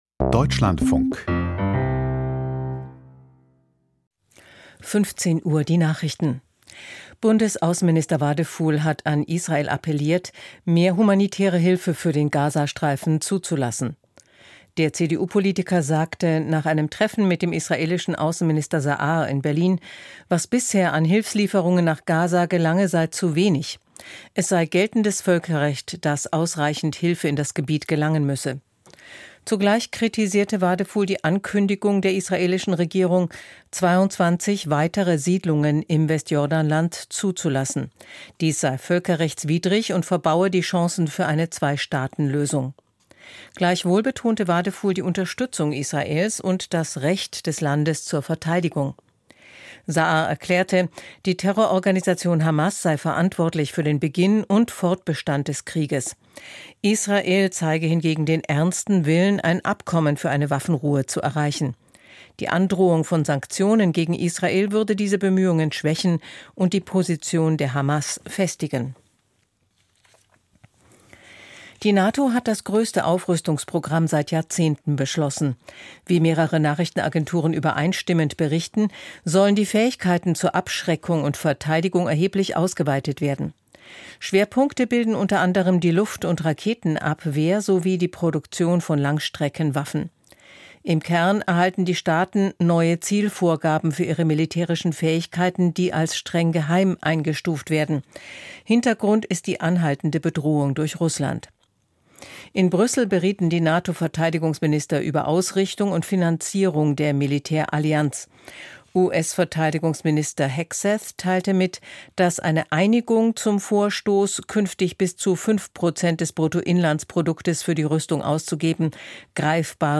Die Nachrichten vom 05.06.2025, 15:00 Uhr
Aus der Deutschlandfunk-Nachrichtenredaktion.